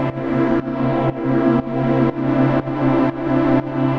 Index of /musicradar/sidechained-samples/120bpm
GnS_Pad-dbx1:4_120-C.wav